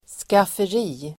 Ladda ner uttalet
Uttal: [skafer'i:]